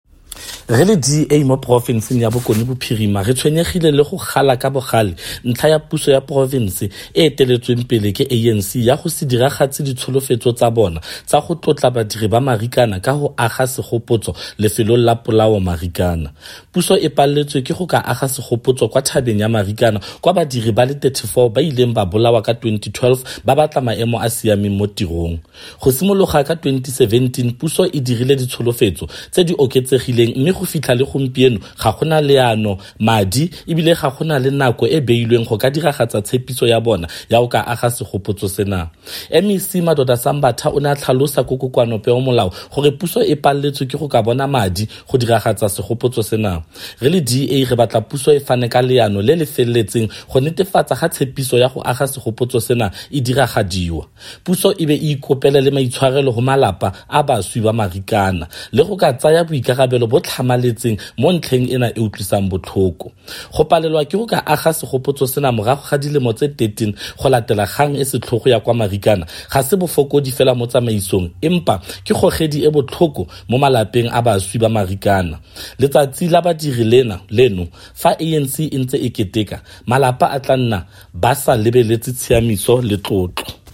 Issued by Freddy sonakile – DA Caucus Leader in the North West Provincial Legislature
Note to Broadcasters: Please find attached soundbites in
Setswana by Freddy Sonakile